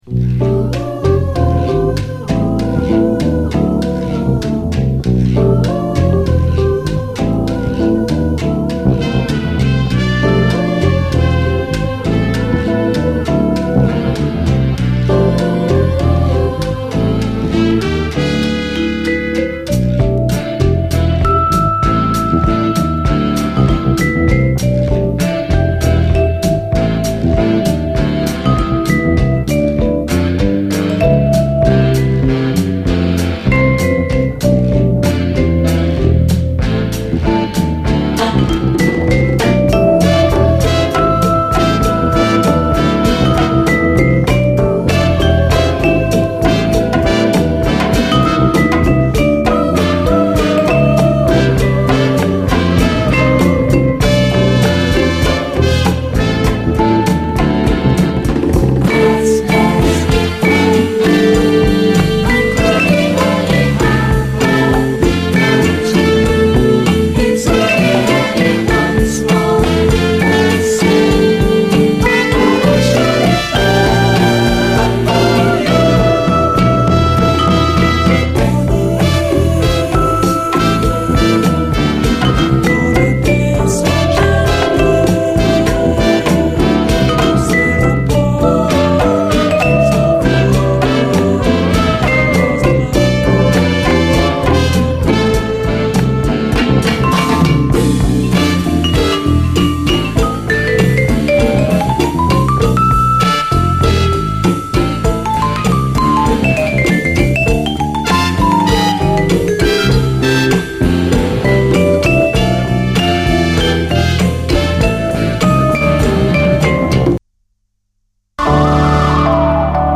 JAZZ FUNK / SOUL JAZZ, JAZZ, LATIN
幽玄なムードでありつつ、しっかりラテン〜ブーガルーのビート感あり！
コーラス、エレピ、ヴァイブが幽玄なムードを醸し出しつつ、しっかりラテン〜ブーガルーのビート感があるトコがまたタマラナイ！